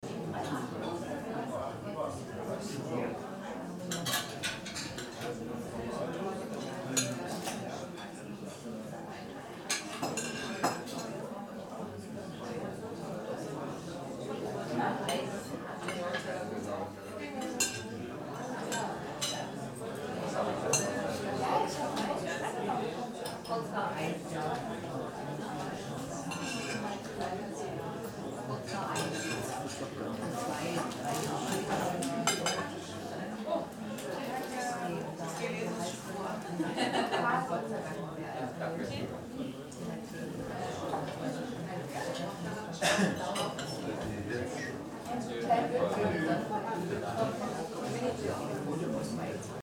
RESTAURANTE RESTAURANT
Tonos gratis para tu telefono – NUEVOS EFECTOS DE SONIDO DE AMBIENTE de RESTAURANTE RESTAURANT
Ambient sound effects
Restaurante_restaurant.mp3